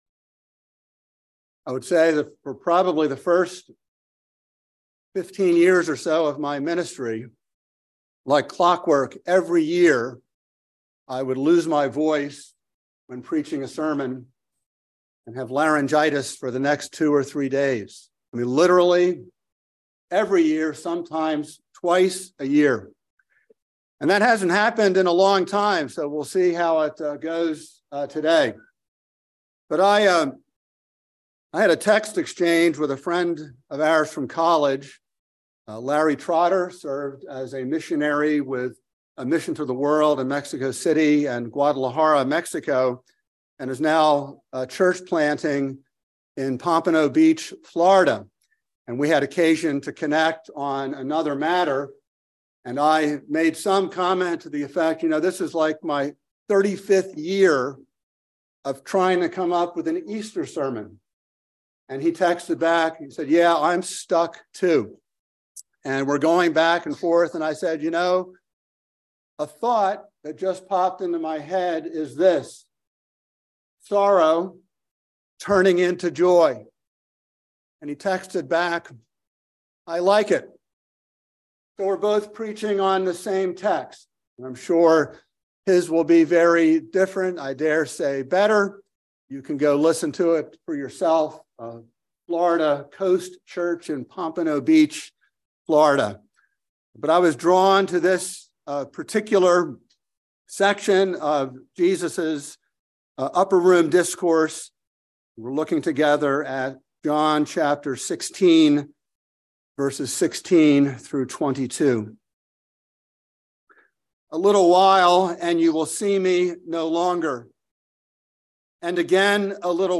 by Trinity Presbyterian Church | Apr 12, 2023 | Sermon